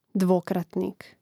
dvòkratnīk dvokratnik